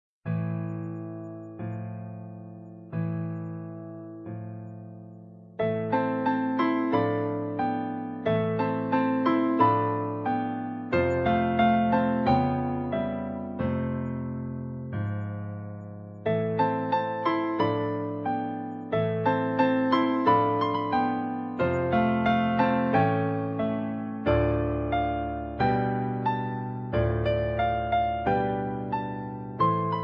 Besetzung: Klavier vierhändig
moderne und frisch klingende vierhändige Klavierstücke